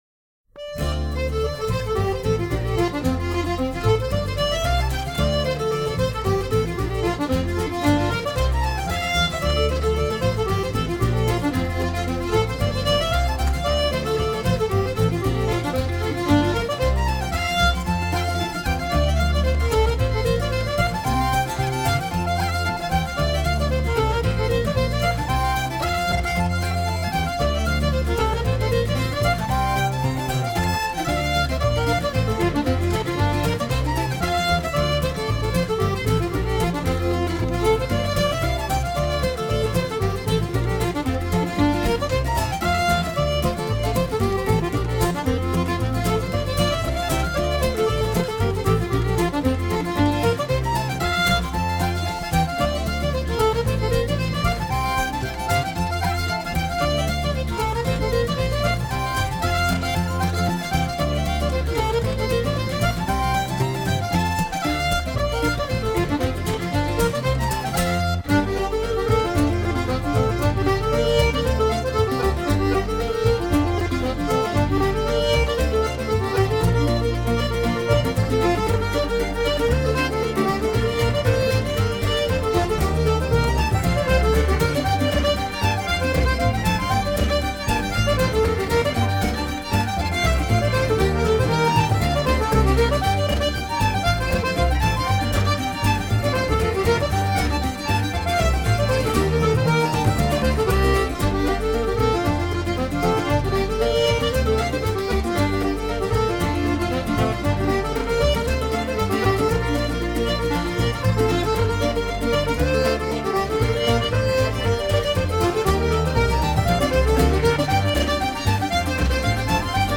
风格清新自然，演录表演完美无瑕。
仍然是实验性的融合风格